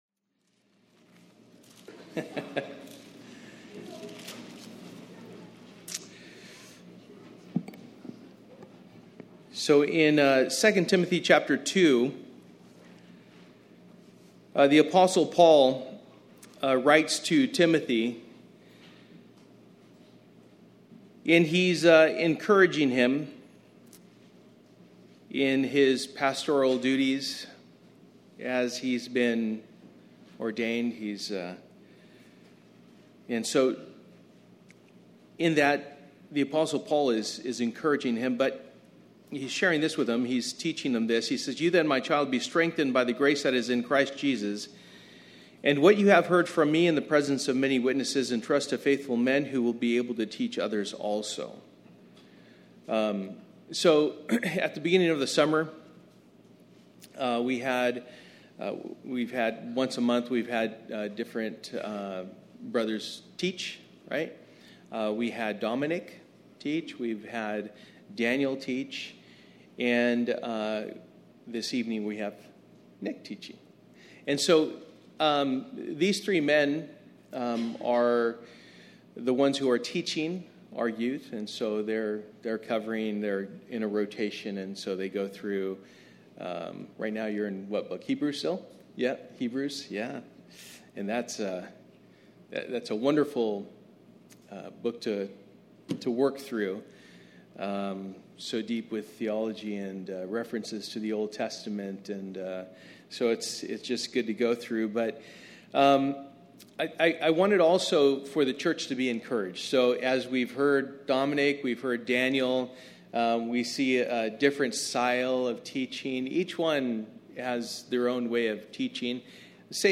Through the Bible Passage: Philippians 1: 1-26 Service: Wednesday Night « What are You interested in